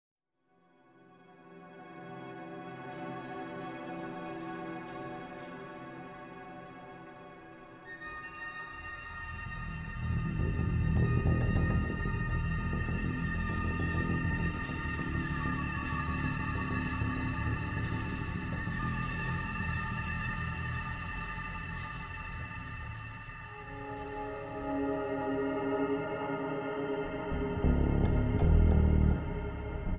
Guitars
Bass
Drums
is an extended rock ballad